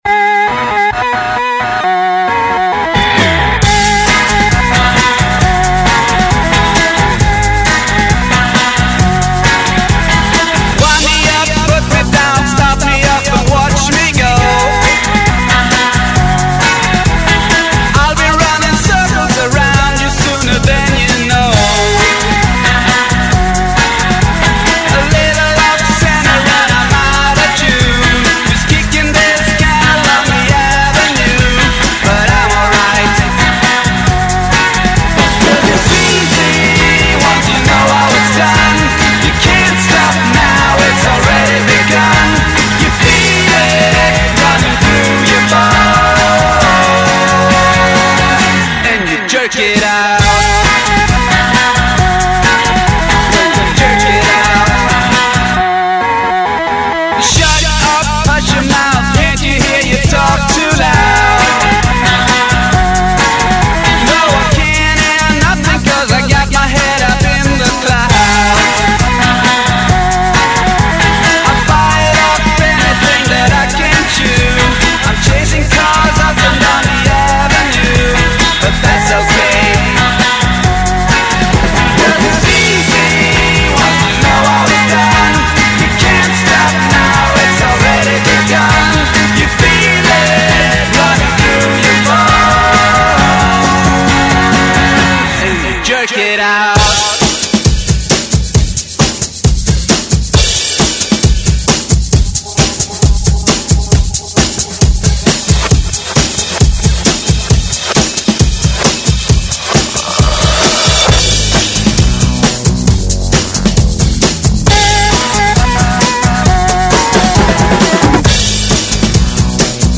raw, raucous fun!